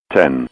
altitude-10ft.wav